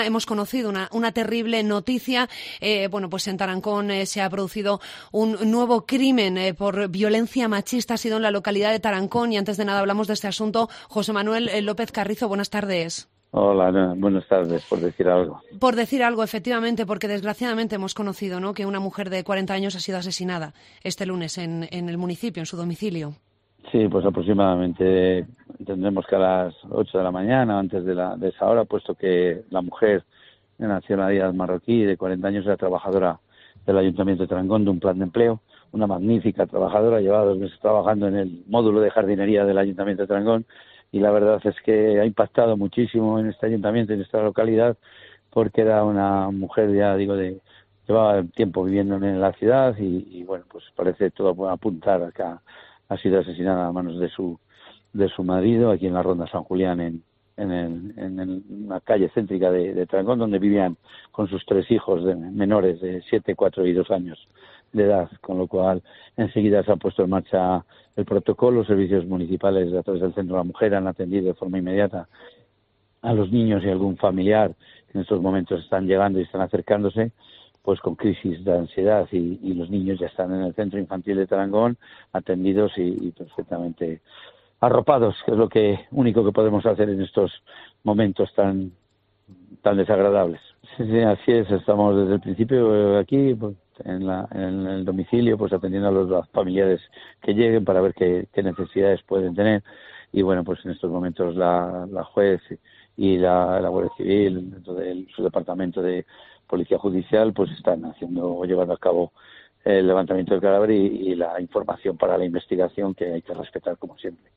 Entrevista a José Manuel Carrizo, alcalde de Tarancón